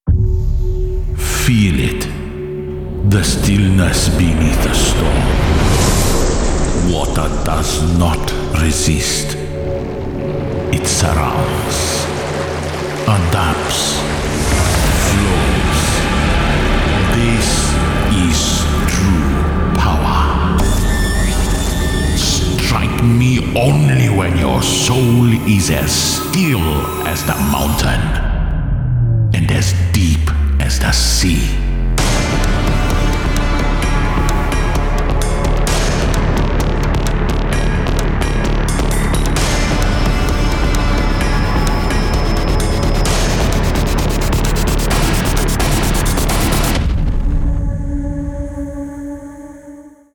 Click to hear the ‘Snake/Turtle’ concept theme